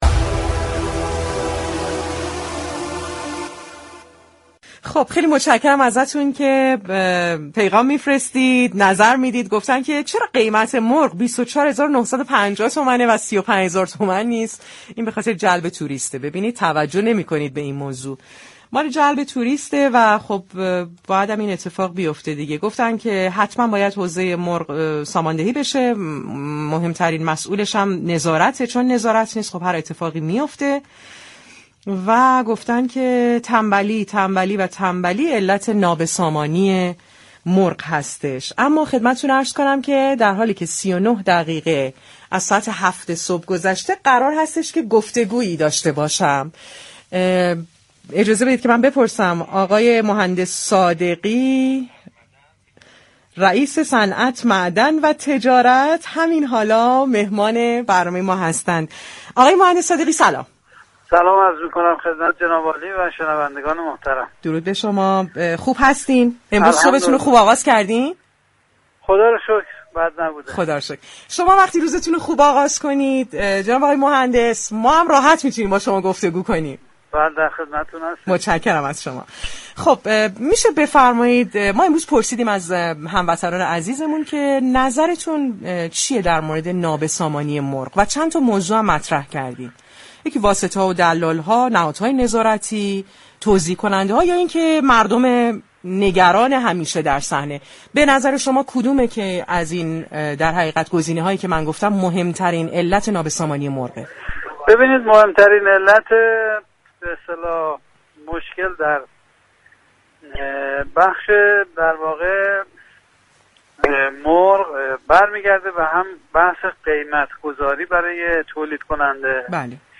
به گزارش پایگاه اطلاع رسانی رادیو تهران، یدالله صادقی رئیس صنعت معدن و تجارت استان تهران در گفتگو با برنامه پارك شهر مهمترین علت مشكل برای تامین مرغ را قیمت گذاری و نارسایی در تامین نهاده های مورد نیاز تولیدكنندگان دانست.